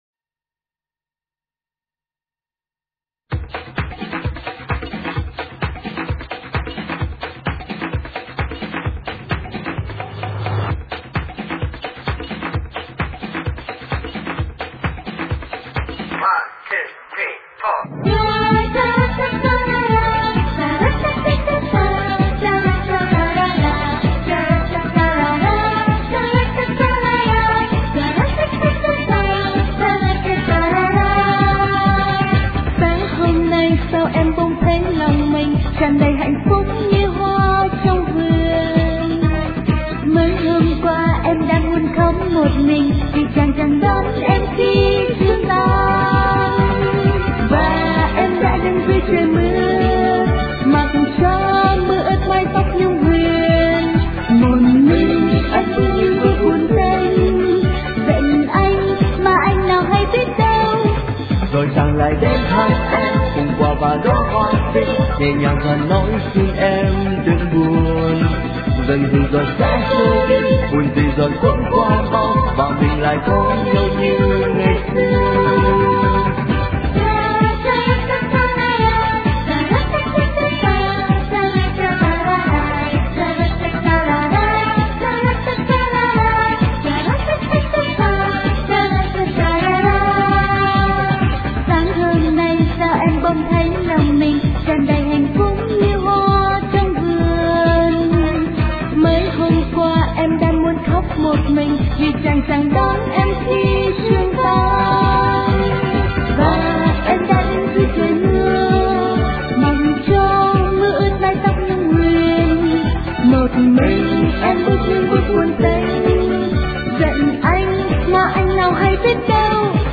* Thể loại: Nhạc Việt